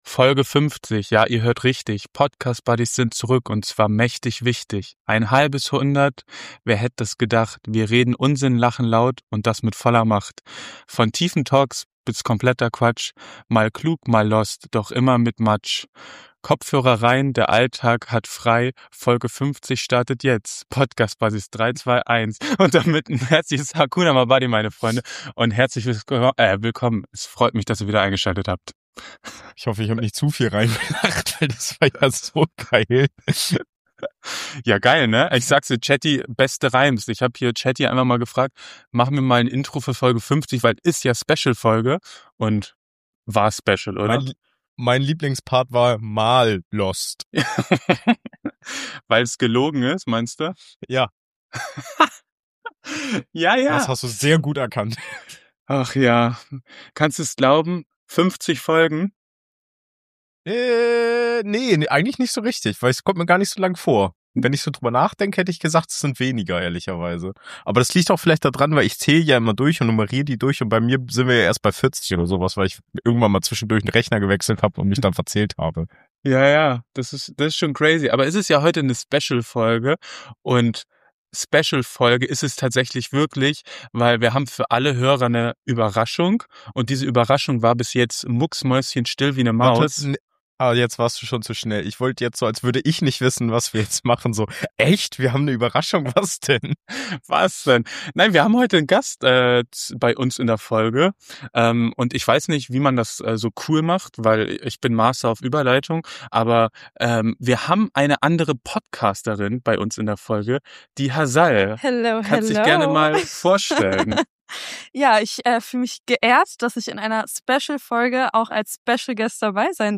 Heute haben wir einen Gast am Start, eine Podcasterin, die uns mitnimmt auf ihre Reise nach Südkorea, mitten rein ins Leben in Seoul.